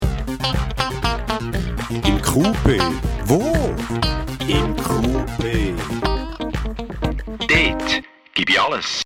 Der Klingelton zum Quartalspraktikum